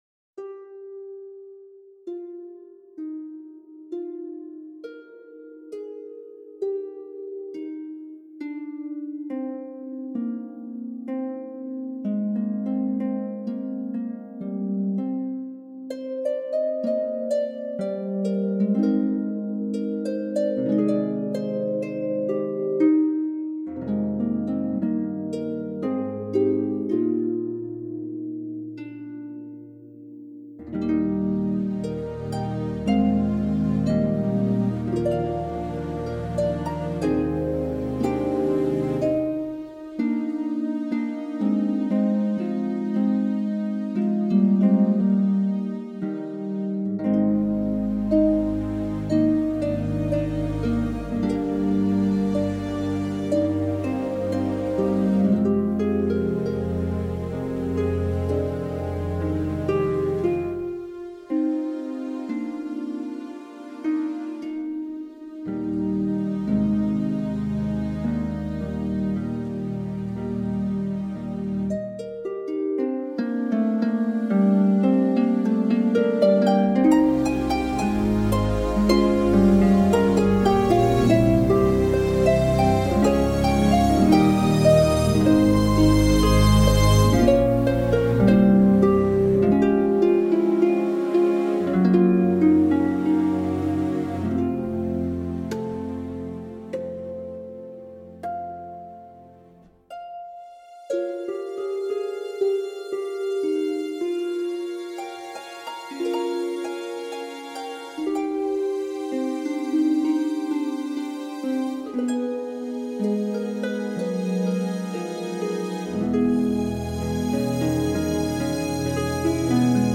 两款完整且灵活的古典竖琴滑音
竖琴插件，分别以Camac和Salvi 47弦音乐会竖琴为原型，并进行了精细采样
竖琴的音色与滑音技巧密不可分，演奏者用手指轻柔地滑过琴弦，创造出梦幻般动听、独具特色的音色